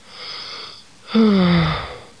00083_Sound_sigh.mp3